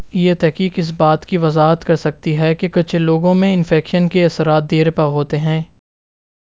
Spoofed_TTS/Speaker_08/110.wav · CSALT/deepfake_detection_dataset_urdu at main